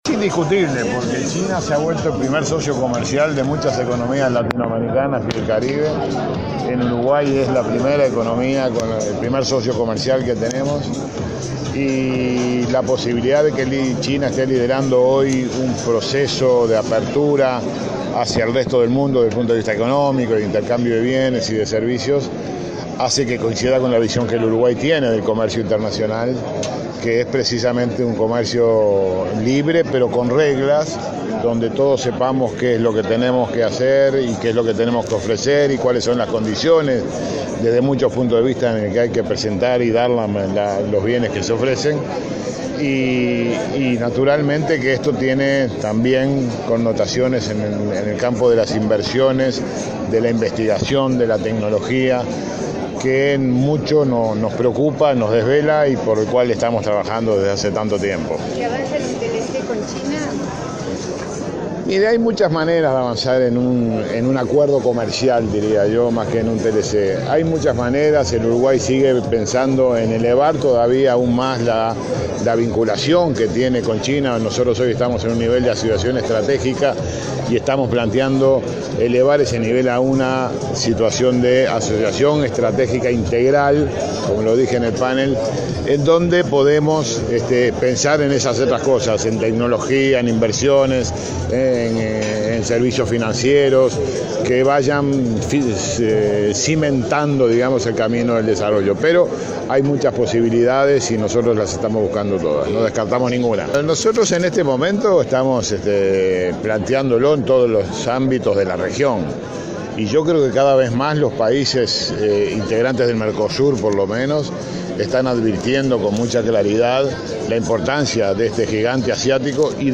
“Hay muchas maneras de avanzar en un acuerdo comercial entre Uruguay y China y no descartamos ninguna”, enfatizó el canciller Rodolfo Nin Novoa a la prensa durante la XI Cumbre Empresarial China-LAC.